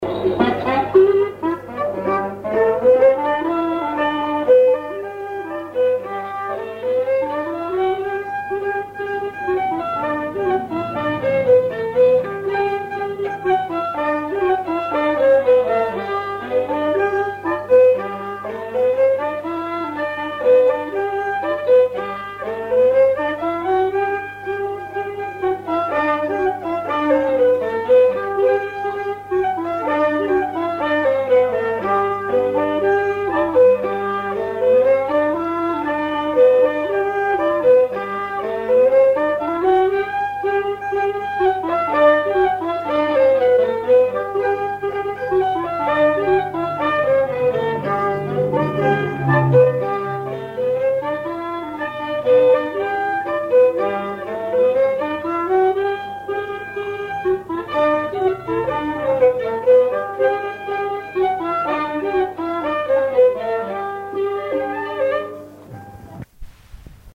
Mémoires et Patrimoines vivants - RaddO est une base de données d'archives iconographiques et sonores.
Chants brefs - A danser
branle
Pièce musicale inédite